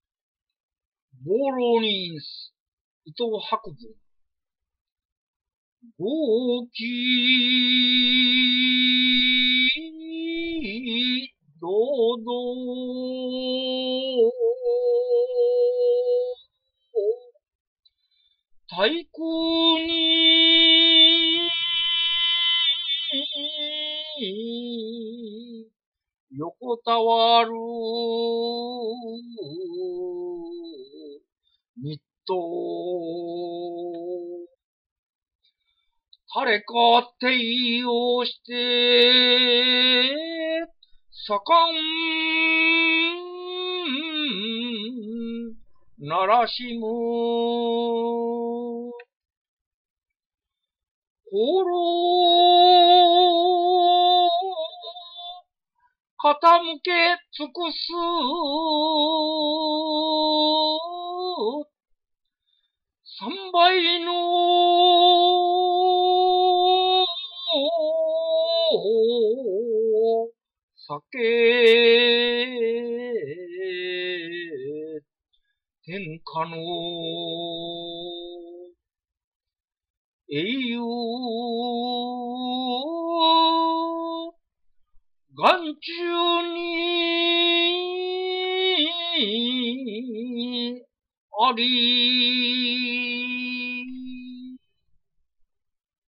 この、博文の自信に満ち溢れた漢詩を ゆったりとした調子で力強く吟じ、その高揚した気持ちを味わいたい。